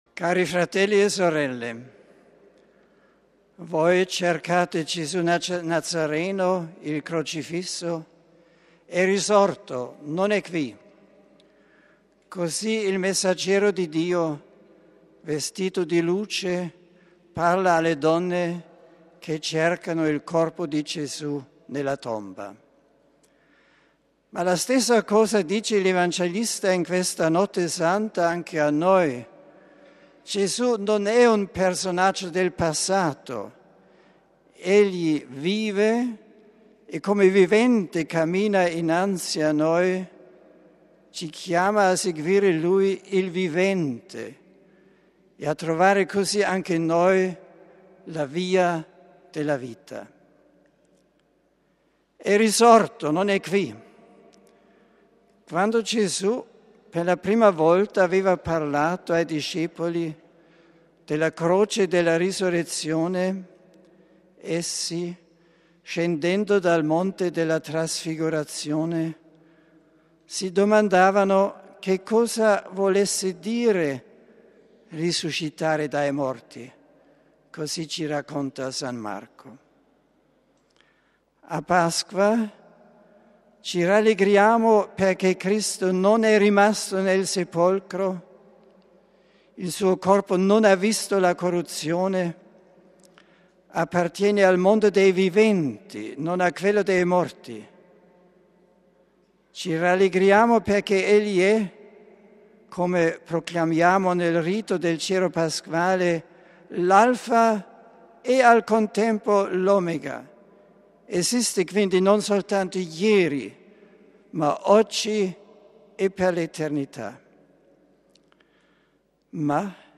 Omelia del Santo Padre per la Veglia Pasquale